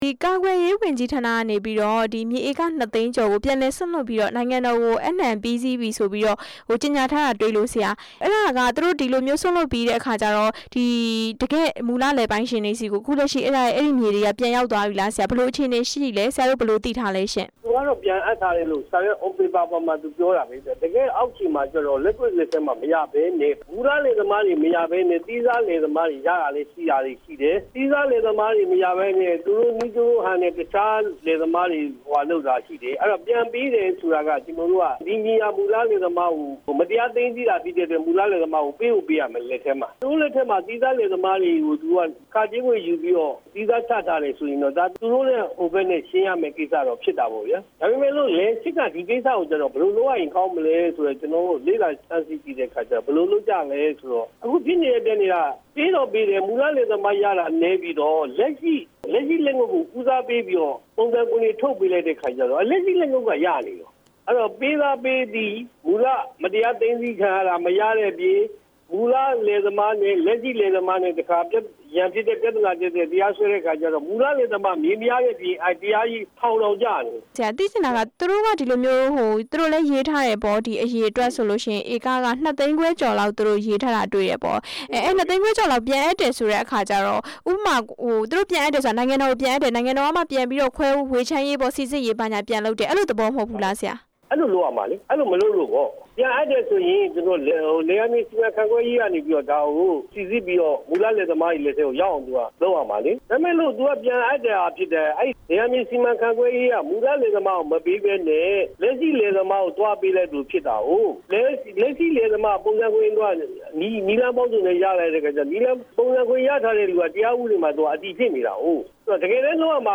ကာကွယ်ရေးဝန်ကြီးဌာန စွန့်လွှတ်တဲ့ လယ်ယာမြေအရေး ဆက်သွယ်မေးမြန်းချက်